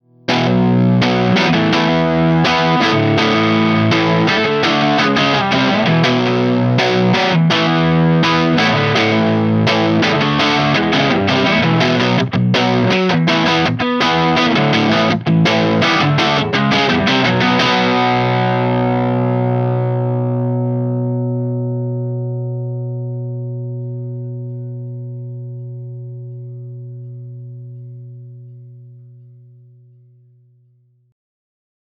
18 Watt v6 - EL84 Dirty Mesa V30
Note: We recorded dirty 18W tones using both the EL84 and 6V6 output tubes.